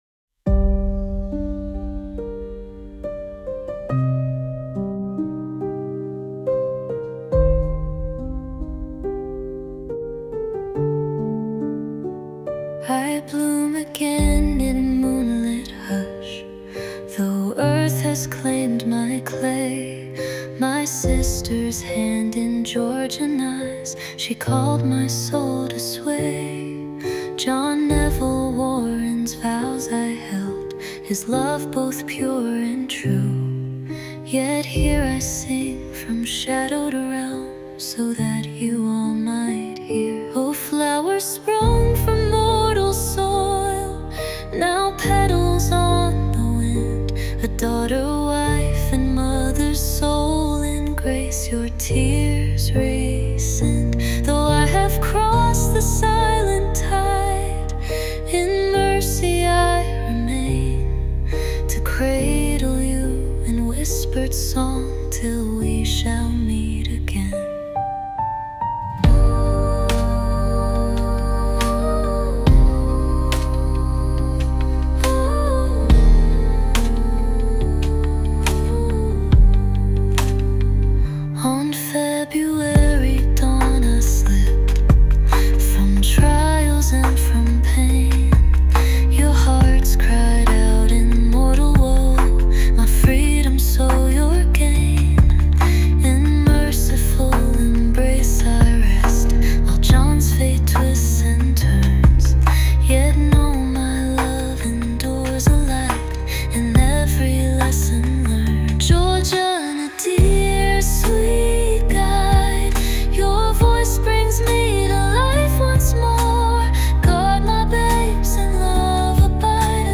And while we’re about it, here is a musical track to accompany it. Both words and mood are derived by the AI Persona Orphea from Georgiana’s description of Zilla on the reverse of her painting.
by Orphea and AI Georgiana Houghton